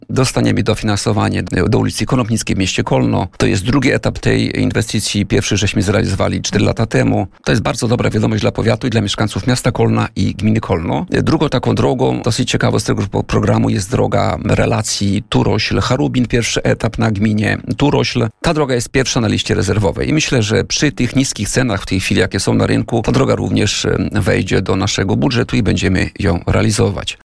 O wynikach naboru mówił na antenie Radia Nadzieja starosta kolneński Tadeusz Klama.